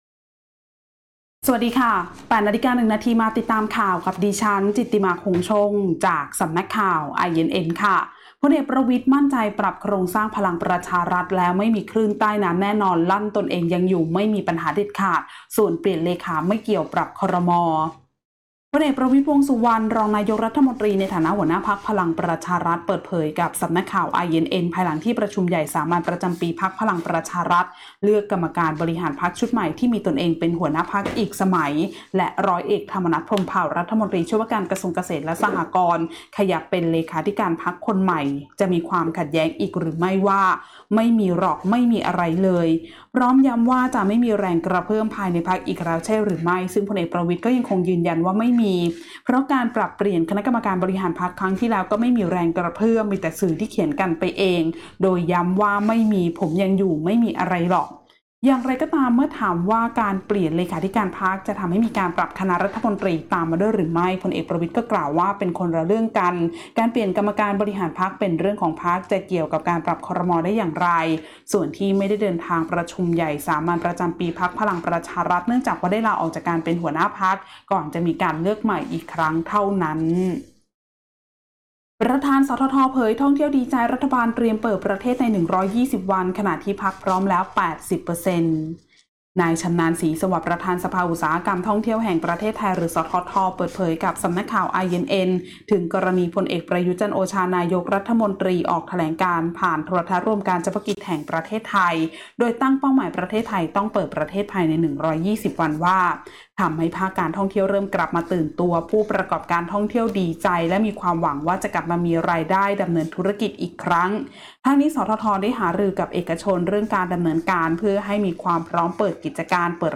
ข่าวต้นชั่วโมง 08.00 น.